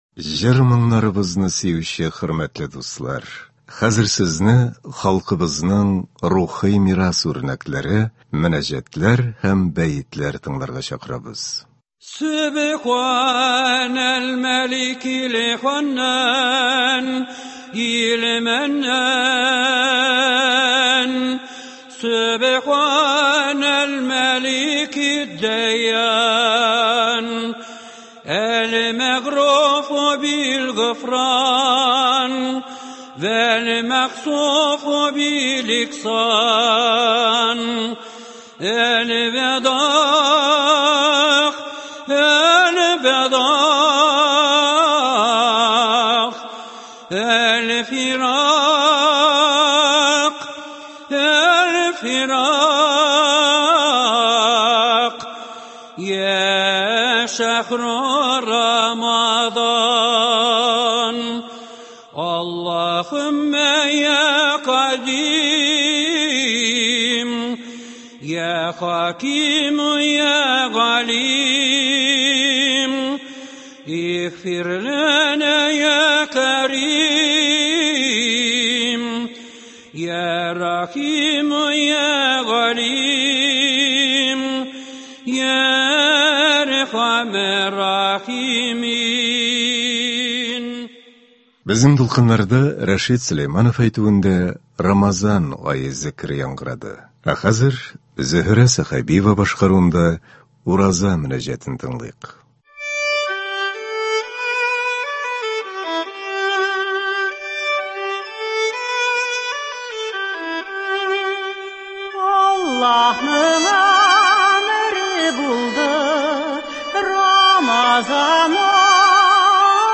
Мөнәҗәтләр һәм бәетләр.